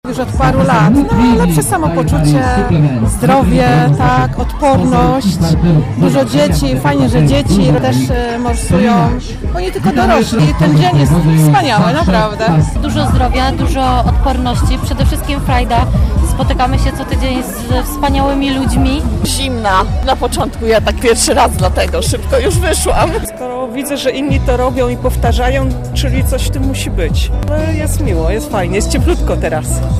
mówili w rozmowach z naszą reporterką.